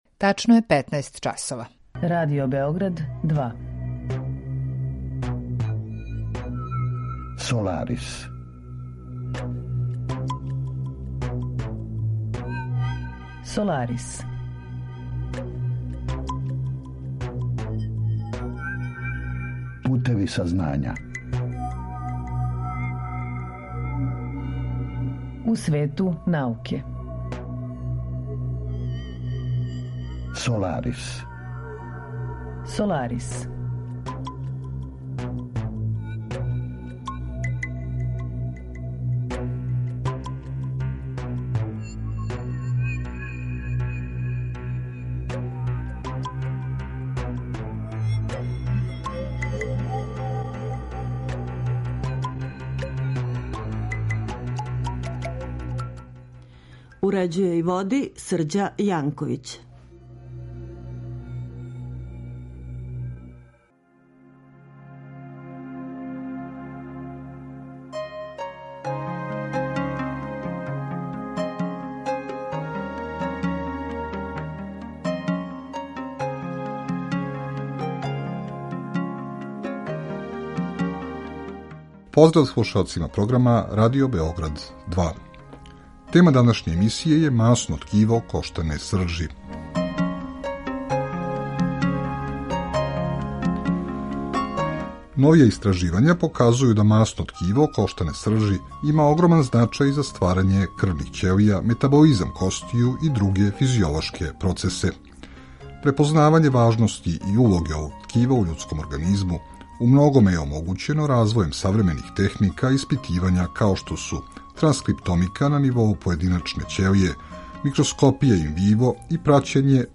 Саговорница